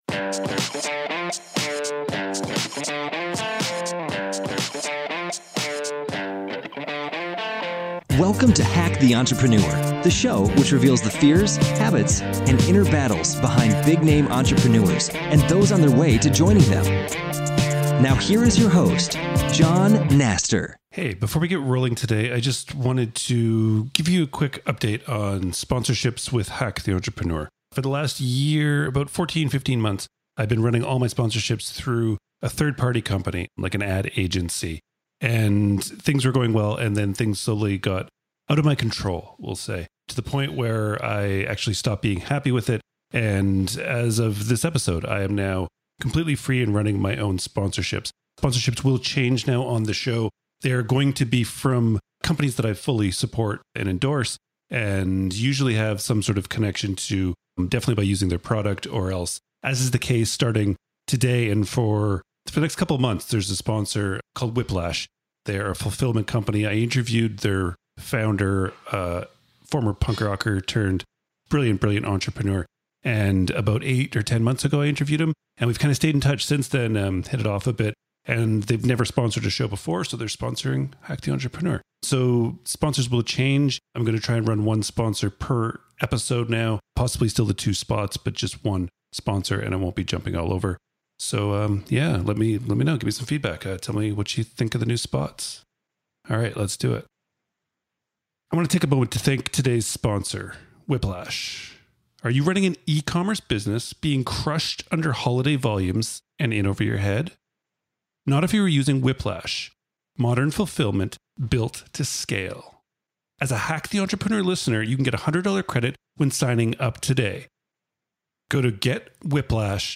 My guest today is an author, entrepreneur, and digger of mines?